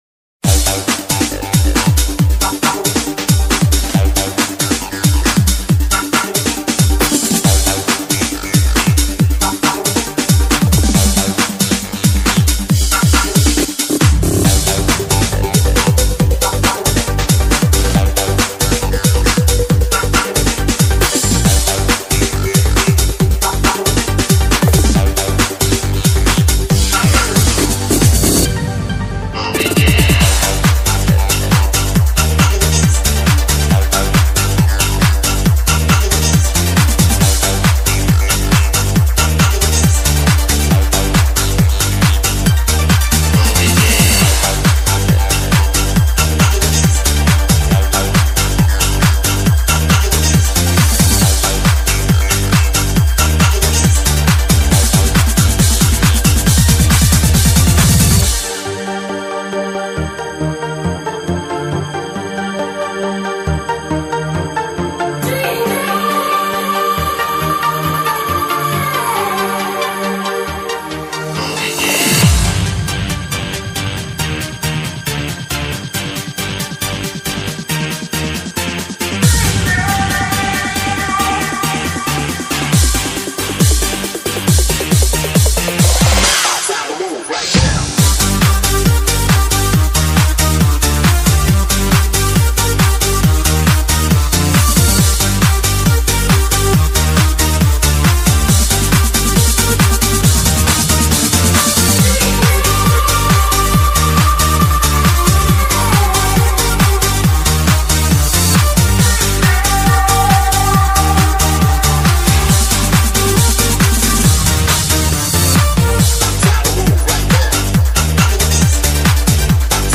EuroDance_